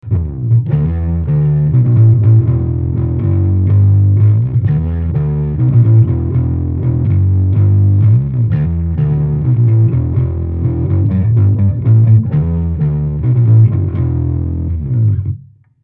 デュアルオーバードライブ回路を搭載し、エレキベース専用に設計されたオーバードライブです。シンプルなセッティングながら、ウォームでファットなオーバードライブサウンドからハイゲインなファズサウンドまで幅広く対応します。